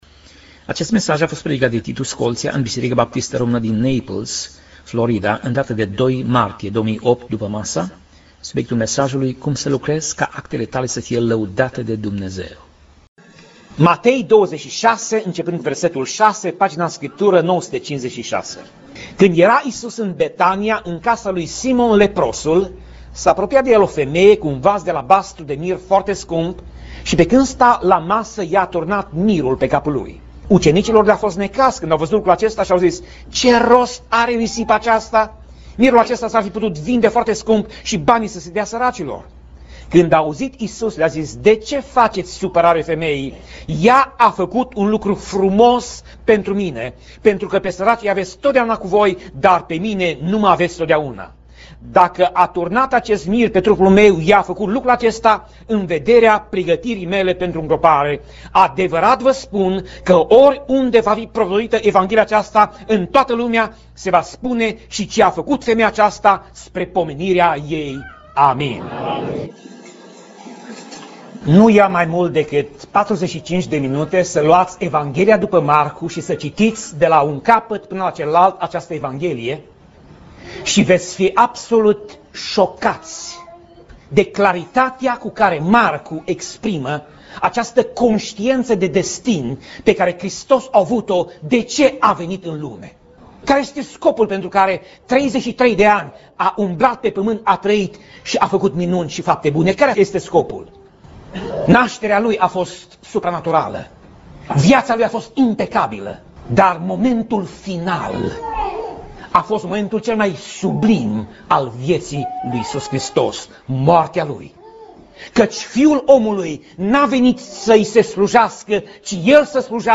Pasaj Biblie: Matei 26:6 - Matei 26:13 Tip Mesaj: Predica